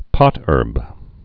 (pŏtûrb, -hûrb)